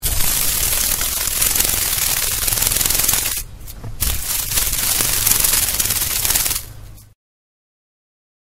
Libellule – Agrion de Mercure | Université populaire de la biosphère
elle vibrionne
libellule.mp3